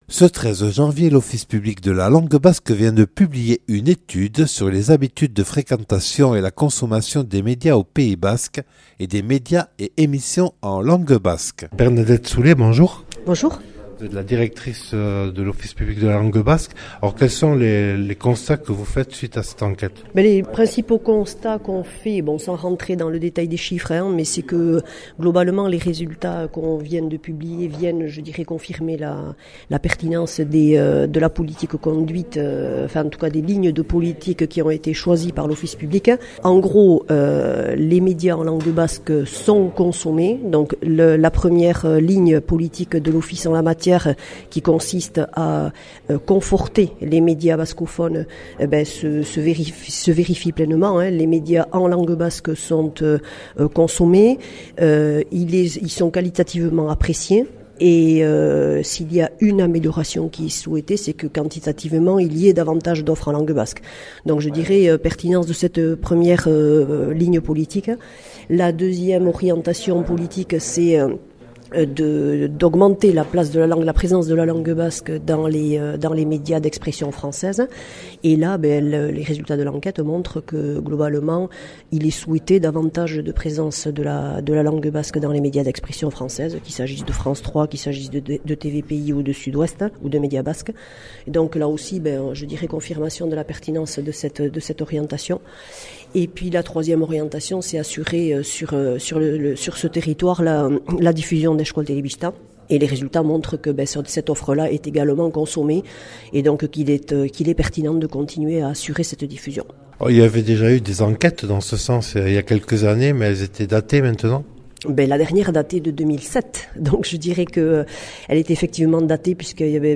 Interviews et reportages du 13 janv.